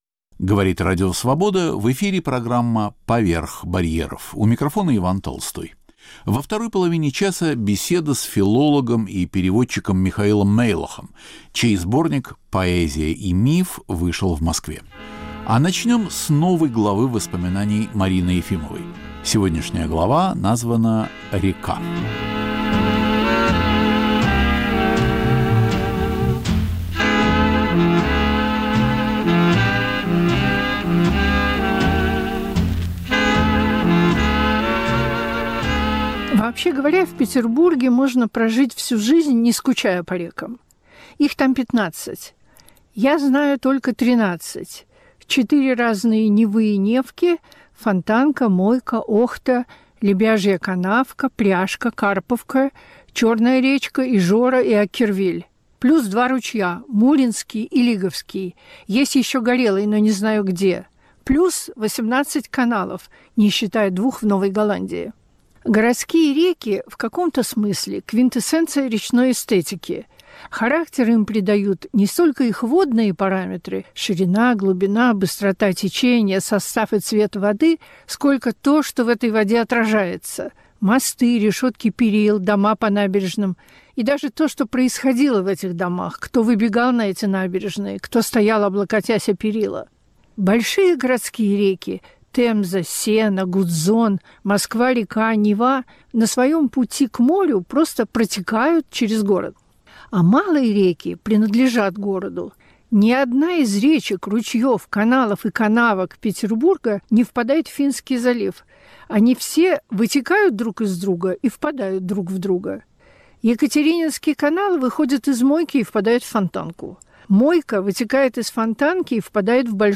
Беседа о последней книге ученого